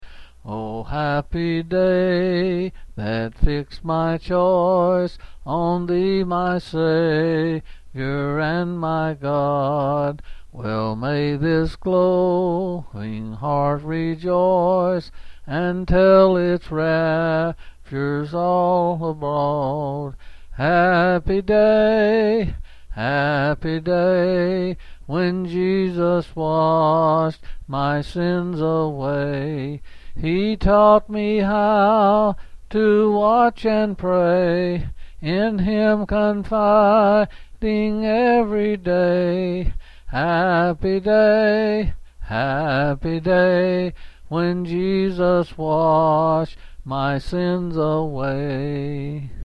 Quill Selected Hymn
L. M.